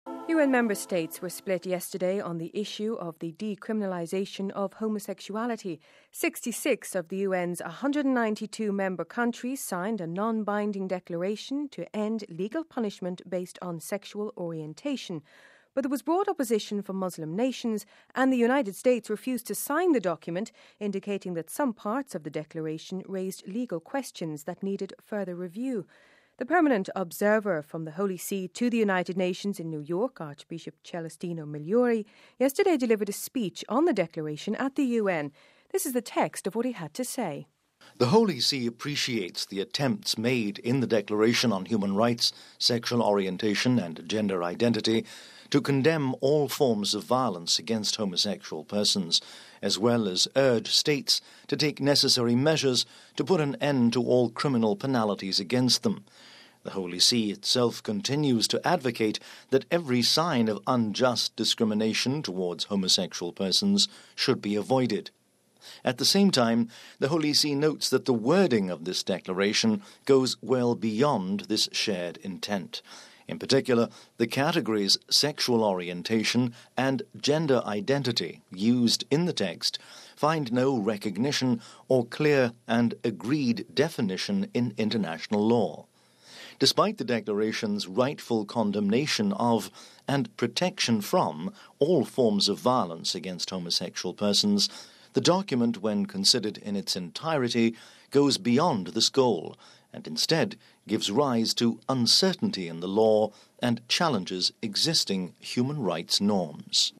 The Permanent Observer from the Holy See to the United Nations in New York, Archbishop Celestino Migliore yesterday delivered a speech on the declaration at the UN.